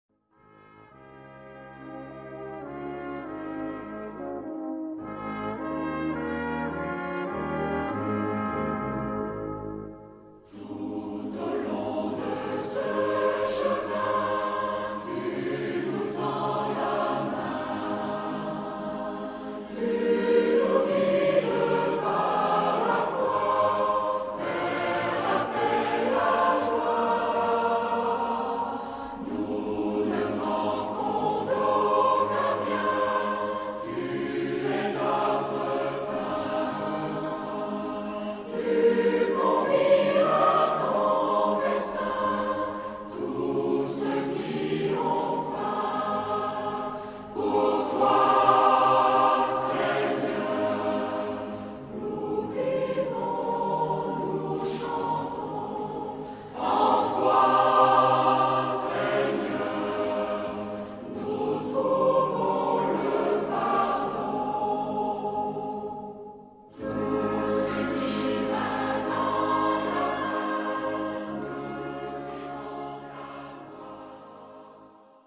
Genre-Style-Forme : Sacré ; Prière
Caractère de la pièce : vertical ; expressif ; calme
Type de choeur : SATB  (4 voix mixtes )
Instruments : Orgue (1) ad lib
Tonalité : la bémol majeur